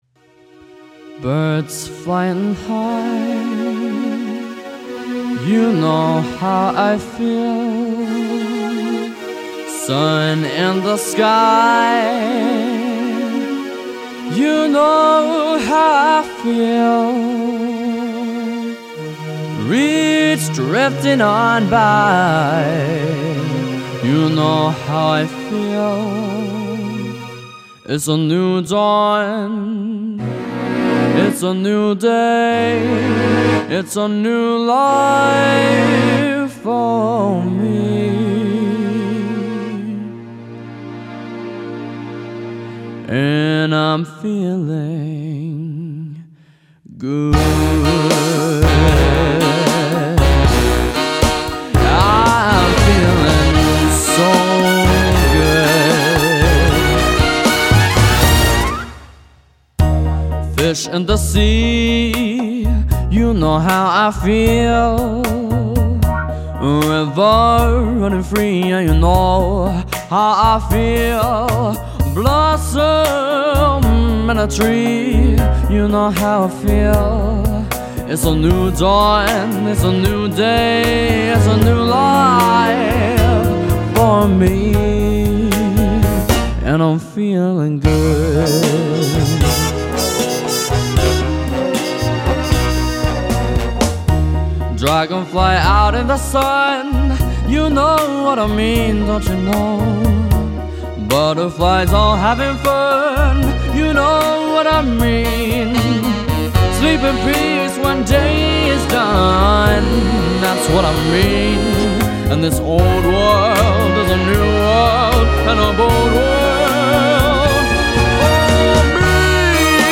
A Musician, Singer/Pianist with Talent Beyond His Years